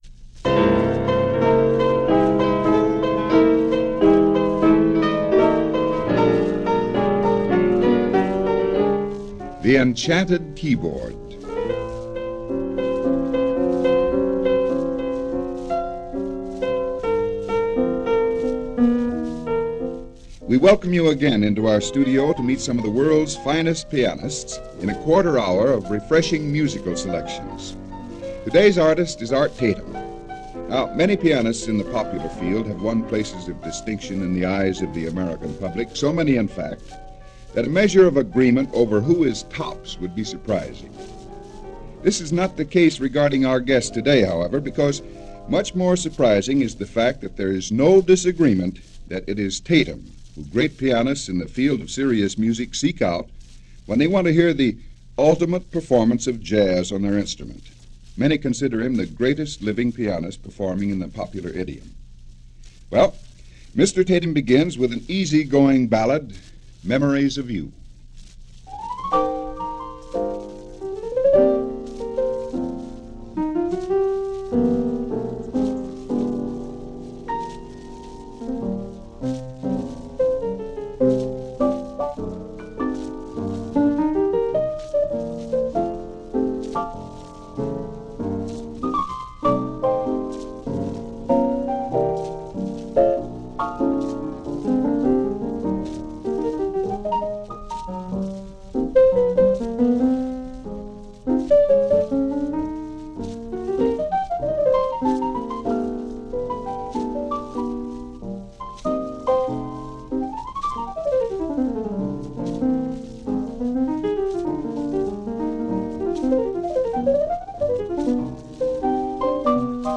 jazz pianists